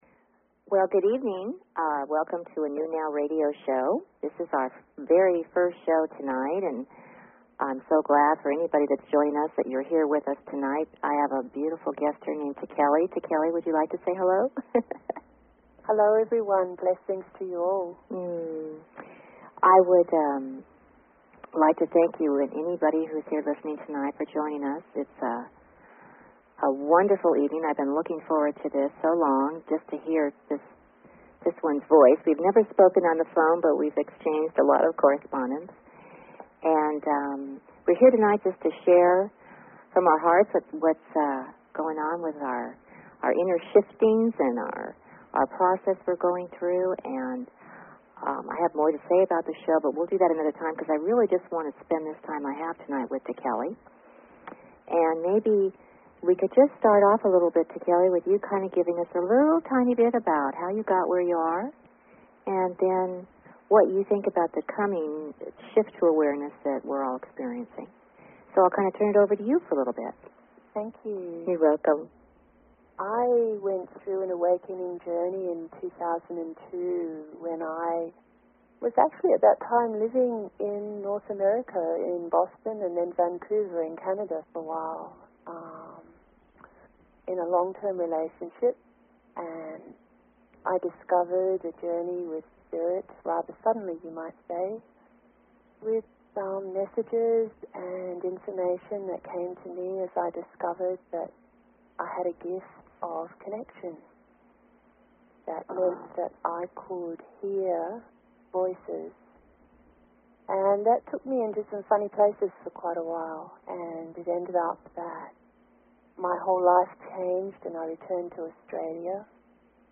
Talk Show Episode, Audio Podcast, The_New_Now and Courtesy of BBS Radio on , show guests , about , categorized as